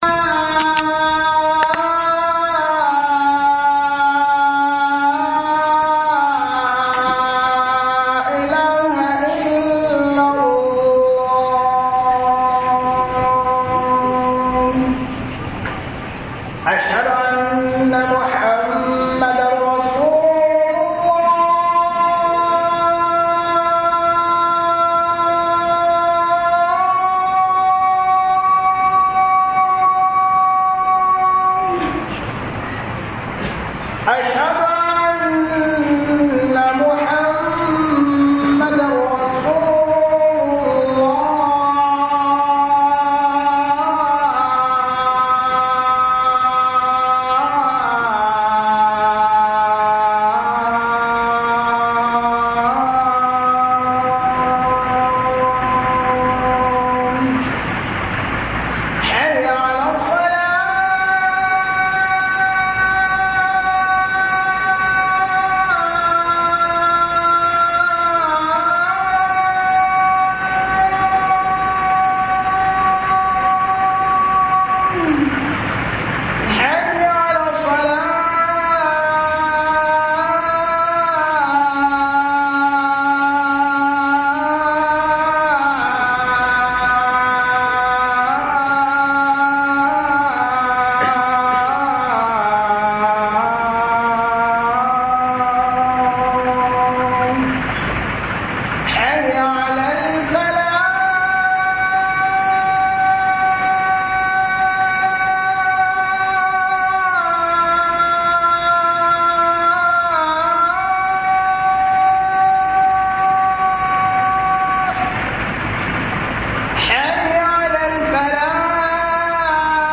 HUDUBA-KAN-AMANA-3 - HUDUBA